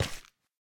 resin_step5.ogg